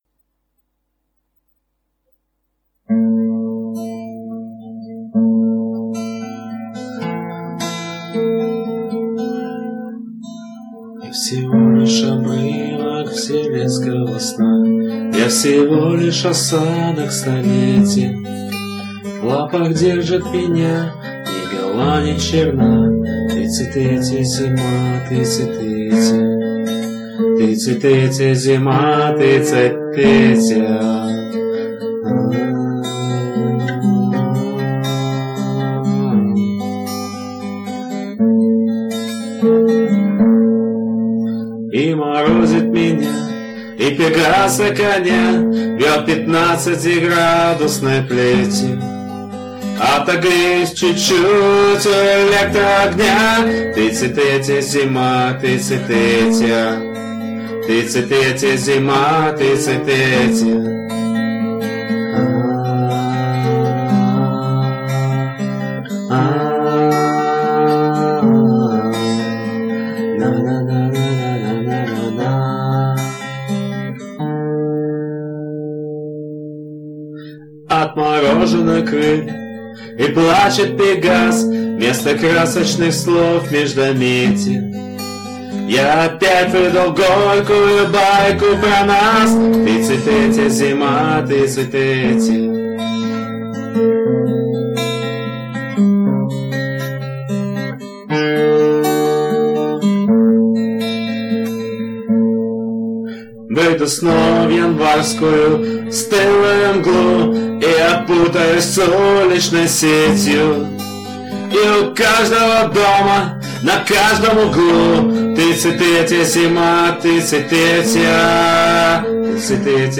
вызвался исполнить это стихотворение под гитару. Живёт он на югах, так что не удивляйтесь, что плеть там всего лишь 15-градусная И заранее просим прощения за перегрузку звука.